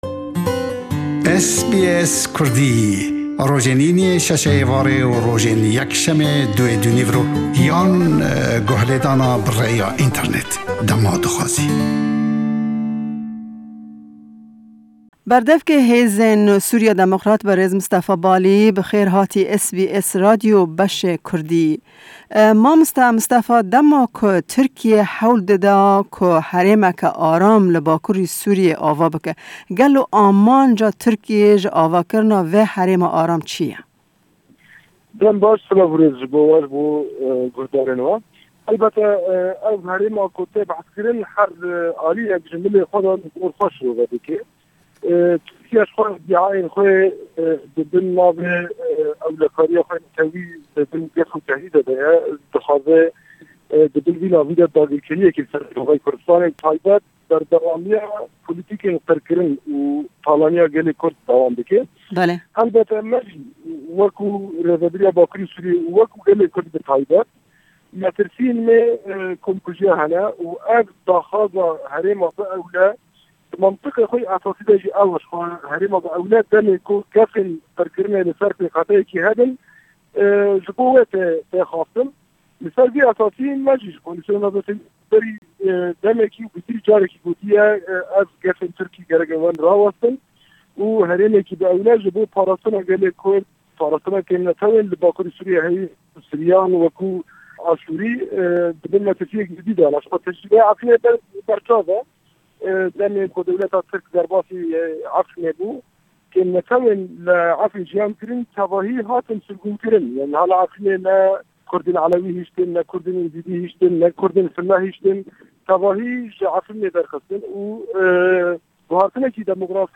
Hevpeyvîneke taybet